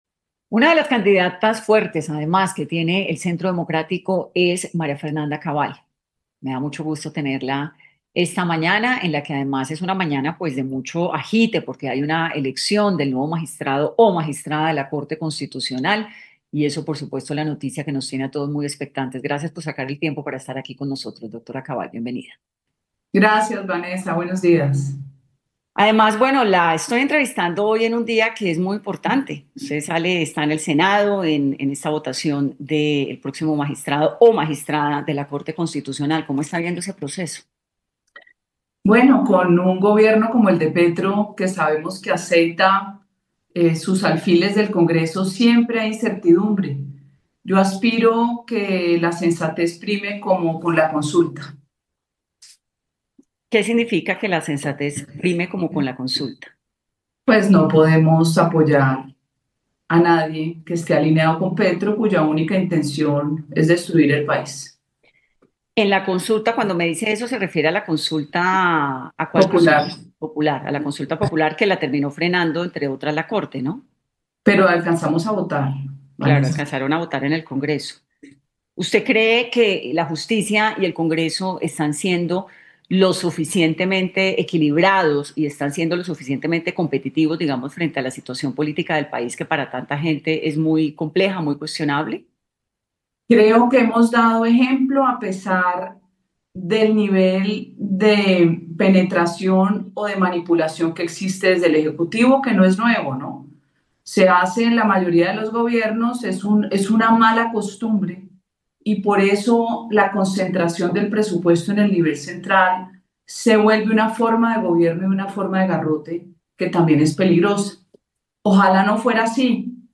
En entrevista con 10AM de Caracol Radio, la precandidata presidencial María Fernanda Cabal destapó su deseo en las elecciones 2026